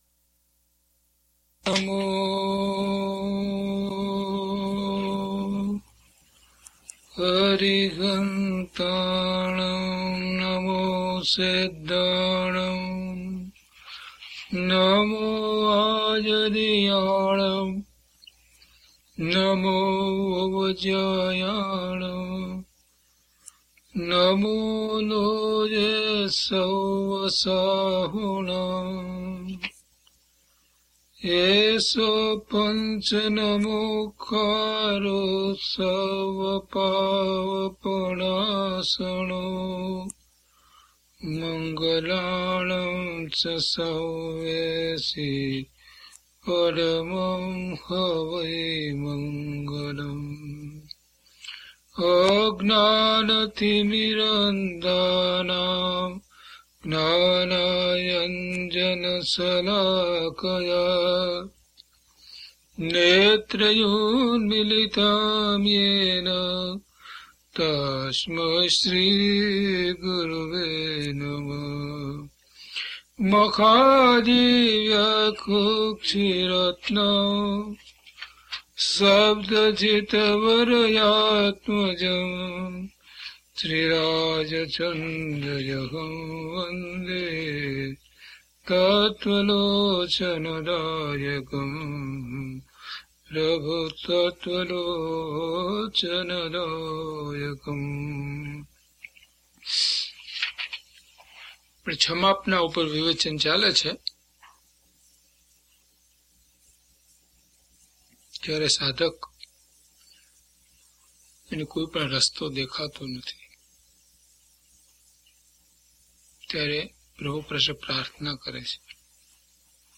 DHP038 Kshamapna Part 2 - Pravachan.mp3